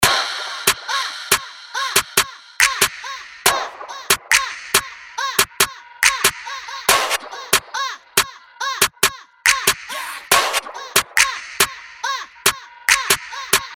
四款高级音色包，共创暗黑风格，专为暗黑街头陷阱音乐和地下节拍打造。
together_152_bpm.mp3